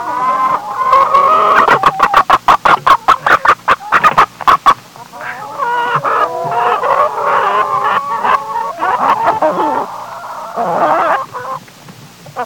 Chicken clucking sound 4
Category: Animals/Nature   Right: Personal